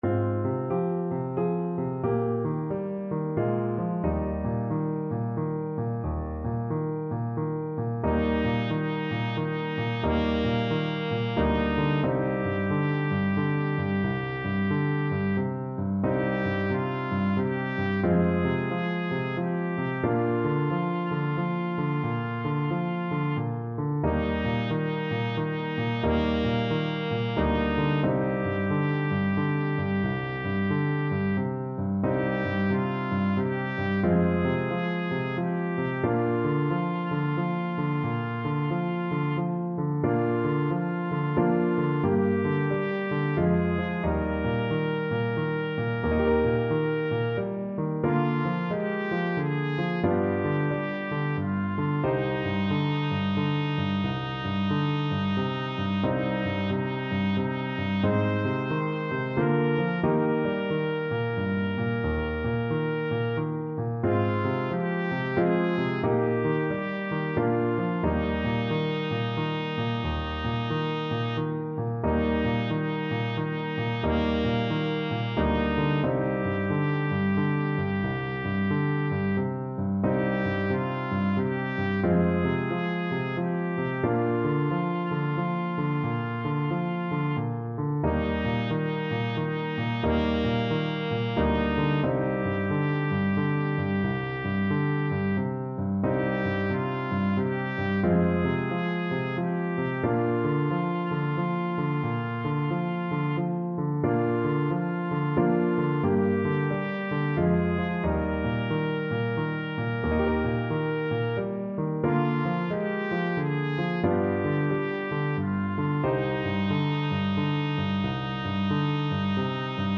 Trumpet version
Trumpet
Eb major (Sounding Pitch) F major (Trumpet in Bb) (View more Eb major Music for Trumpet )
3/4 (View more 3/4 Music)
=90 Andante, gentle swing
Traditional (View more Traditional Trumpet Music)